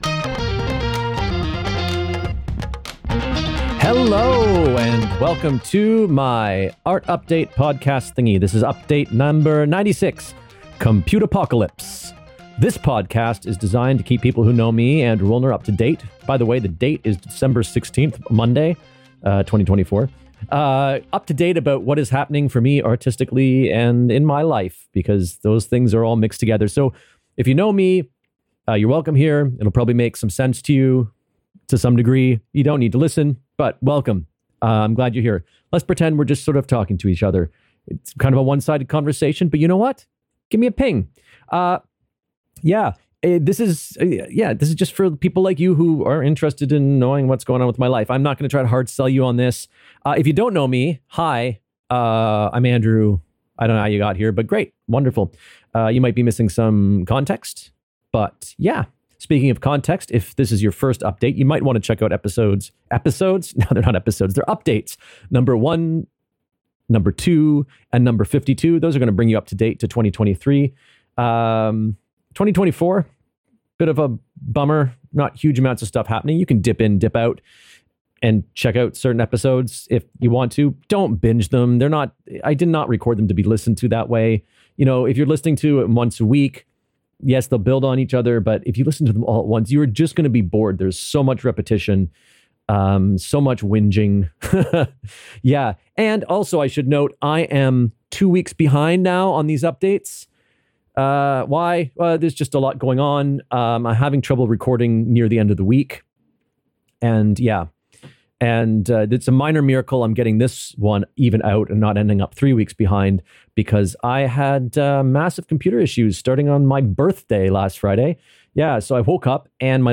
In this update, I talk about how every decent PC in my house decided to have a major hardware failure AT THE SAME TIME, starting on the morning of my birthday. I also touch on the studio visit we did for the upcoming show, INTERFERENCE, and a few other things. And oh! I spliced in the newest algorithm generated song I made (this time for the Nerd Nite trailer).